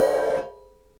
Drum Samples
C r a s h e s